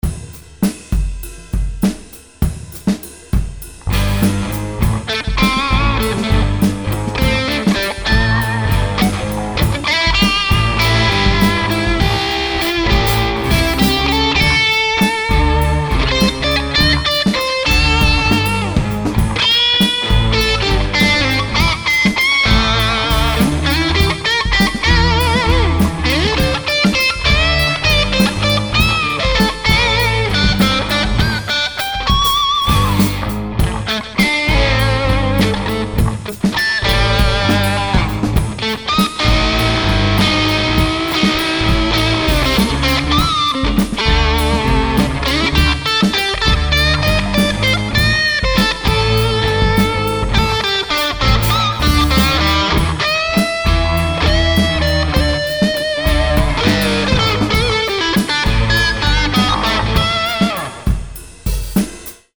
Blues, Telecaster